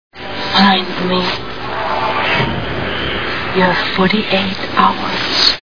Fear Dot Com Movie Sound Bites